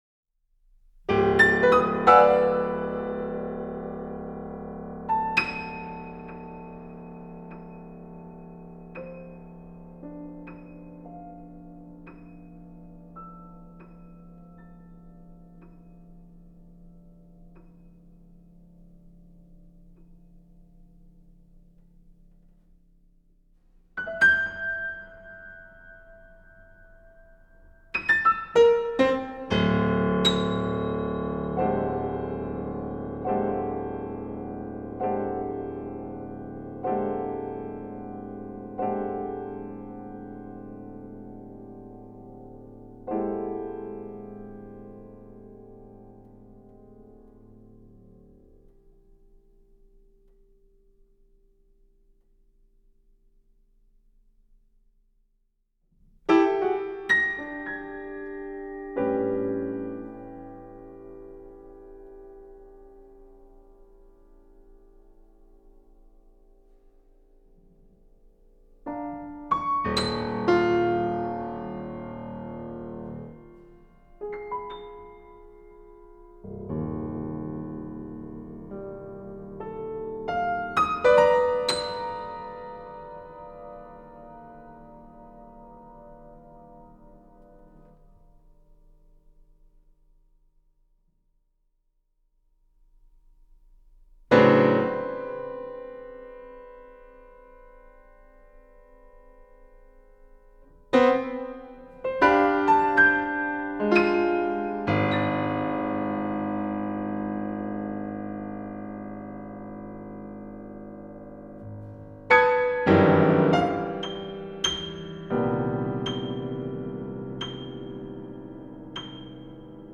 Alé para piano